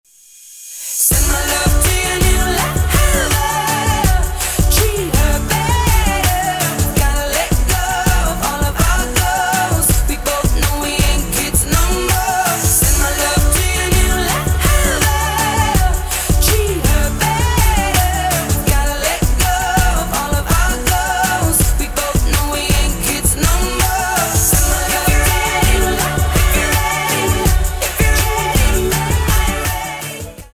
поп
rnb
чувственные